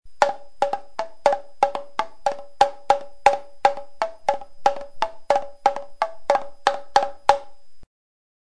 URM Sonos de Sardigna: nuovi strumenti - Tamburelli
Tamburelli.mp3